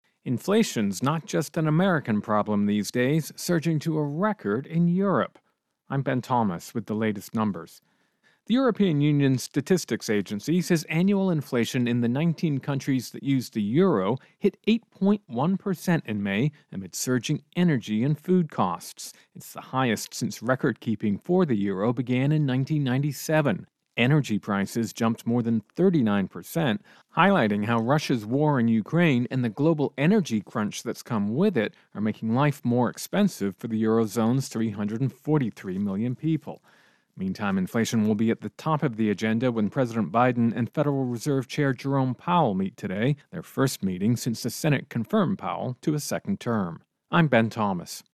Intro and voicer "Europe-Inflation"